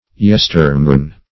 yestermorn - definition of yestermorn - synonyms, pronunciation, spelling from Free Dictionary